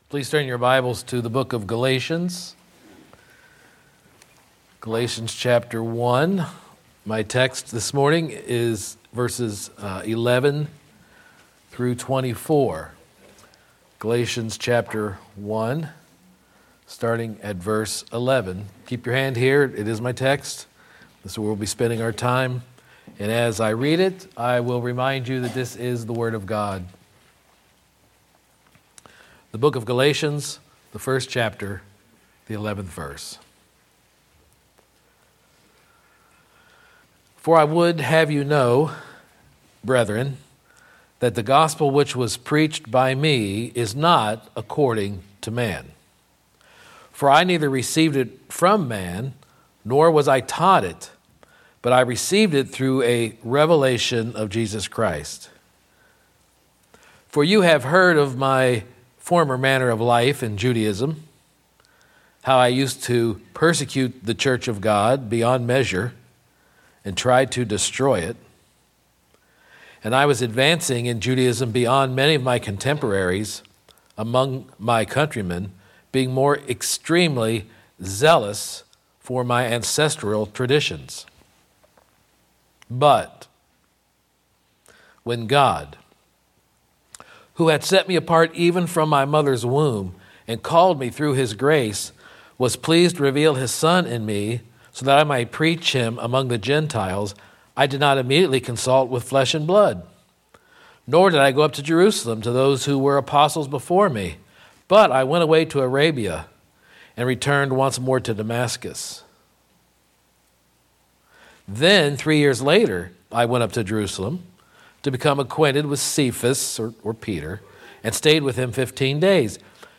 3-16-25-Sermon-Called-By-Grace.mp3